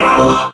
robo_bo_hurt_01.ogg